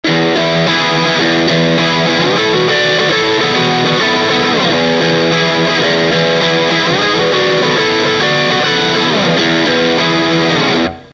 で、「赤」と「青」共に、つまみフルでの音比較です。
Guitar Fender STRTOCASTER
Amplifier VOX AD30VT AC30TB
GAIN全開VOLUME全開